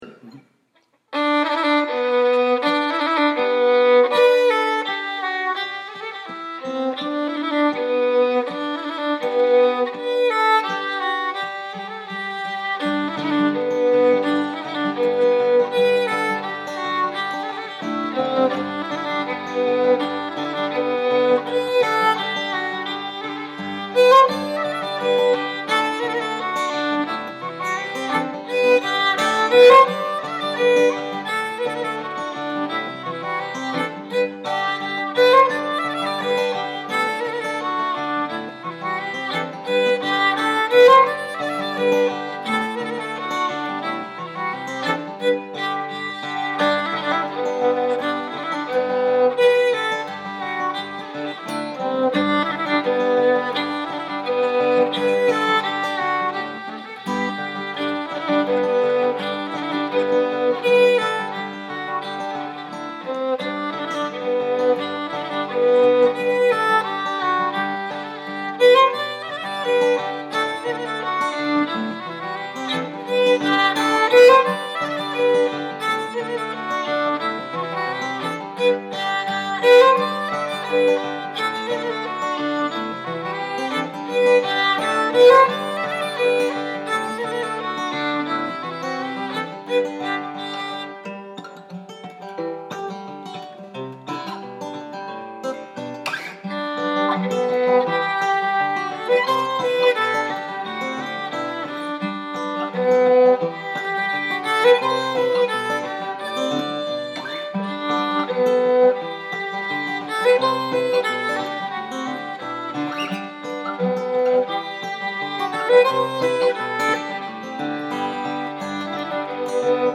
Reel - G Major